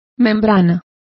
Complete with pronunciation of the translation of membranes.